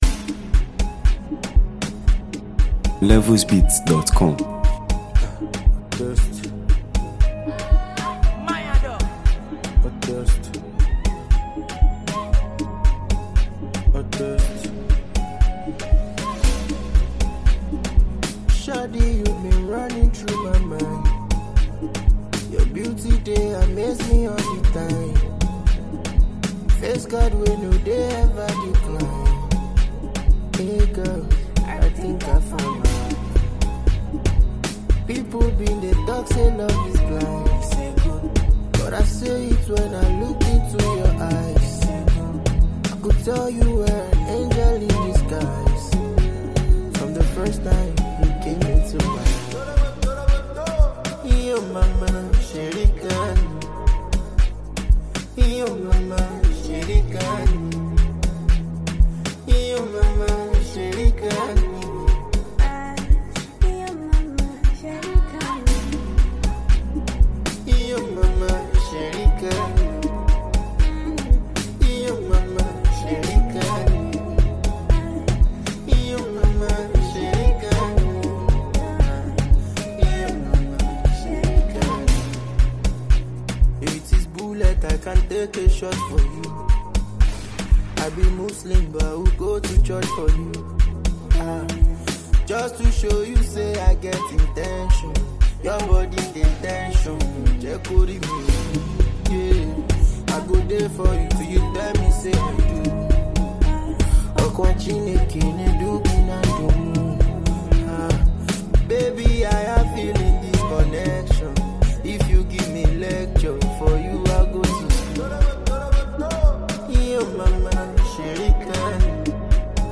This emotional and soul-stirring record
smooth vocals
If you enjoy meaningful Afrobeat sounds and quality music